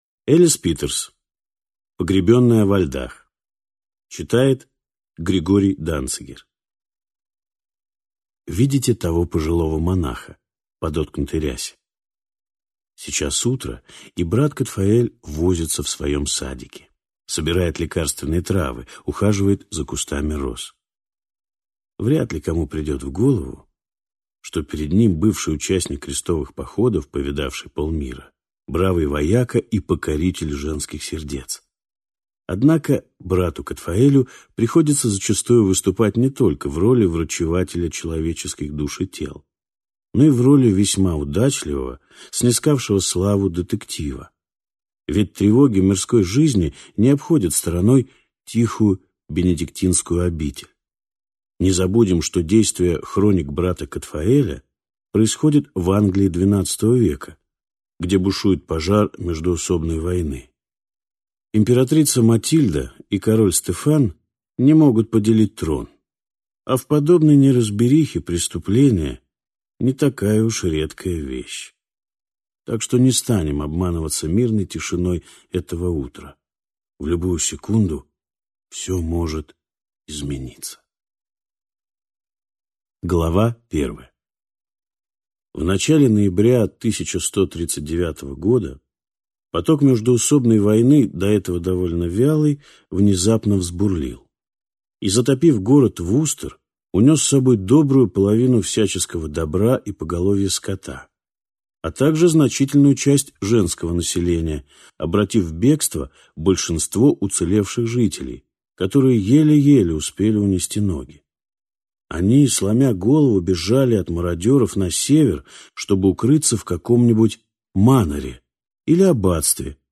Аудиокнига Погребенная во льдах | Библиотека аудиокниг